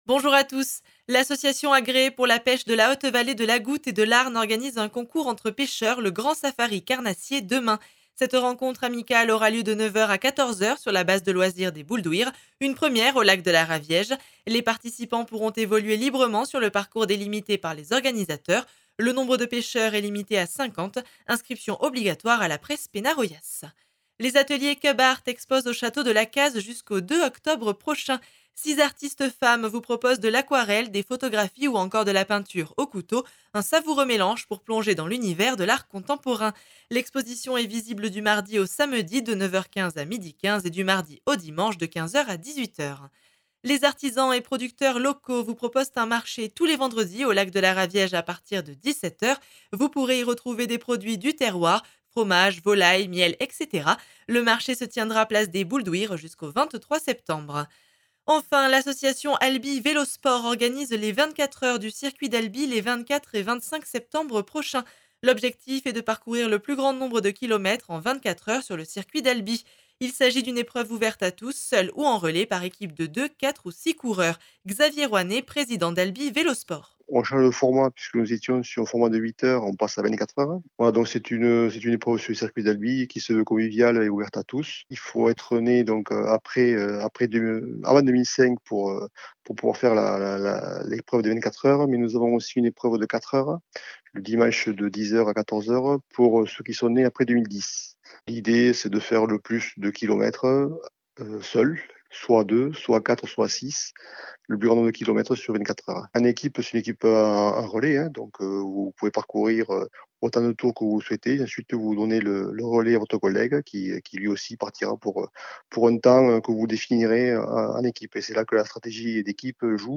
Actualités